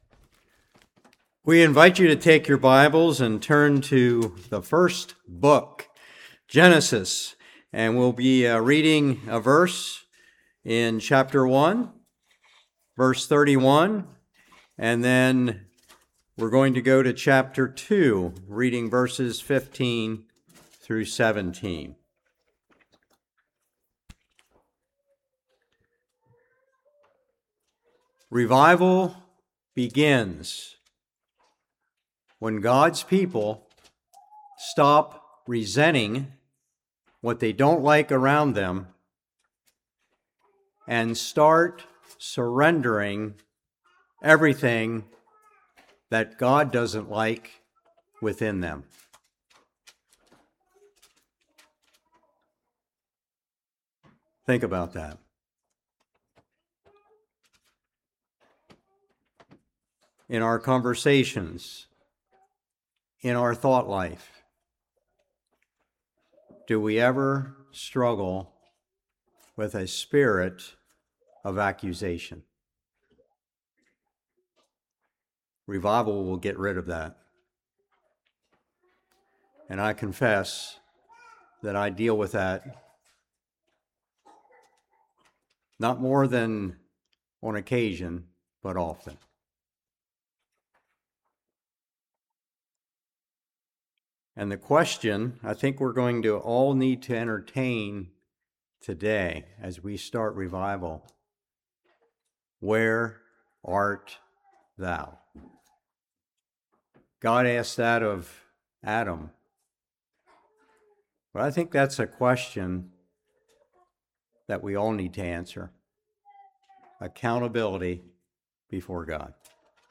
2:15-17 Service Type: Revival Take heed lest he fall.